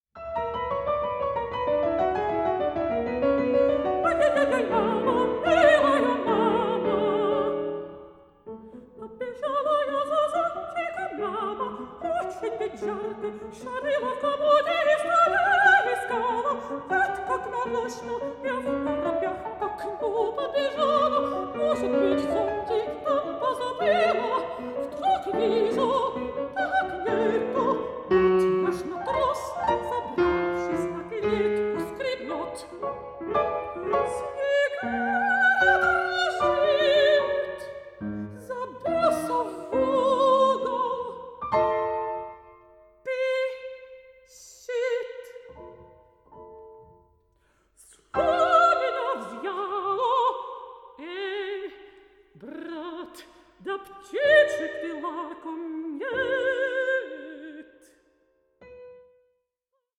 pianist
mezzo-soprano